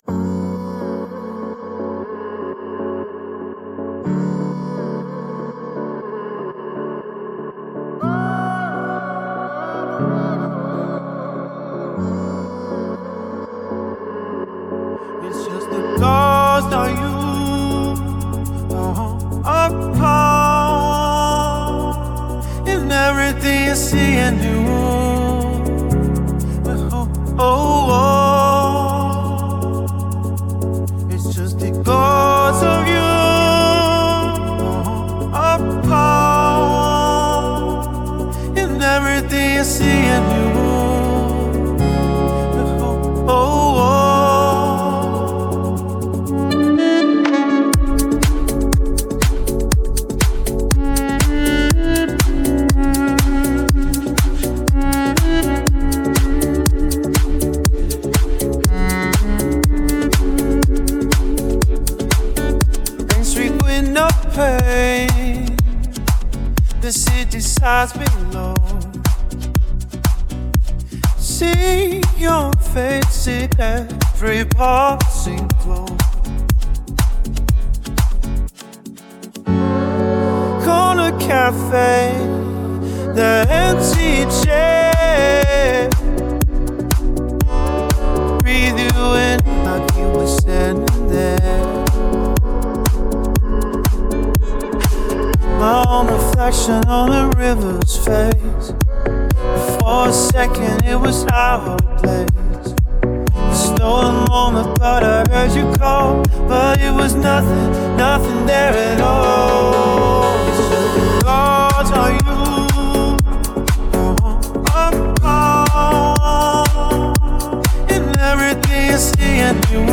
sad bangers